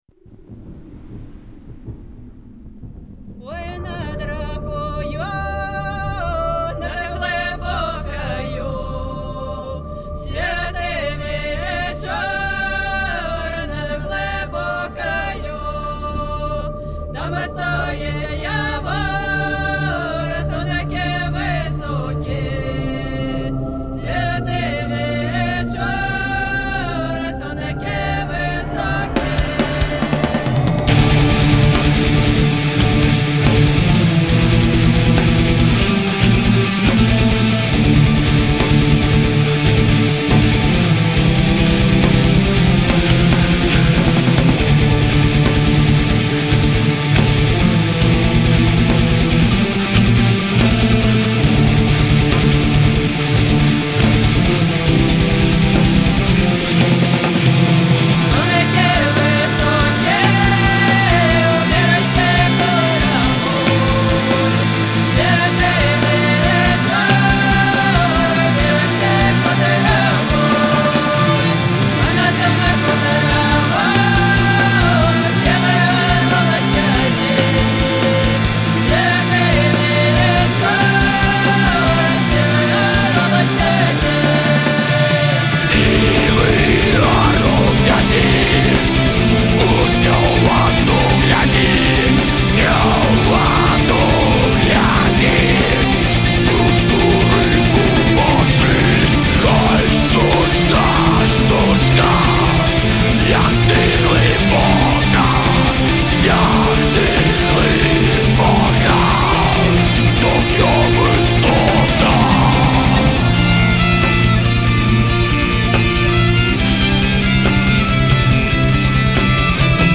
бо ў ёй знойдзенае новае гучаньне беларускага фольк-мэталу.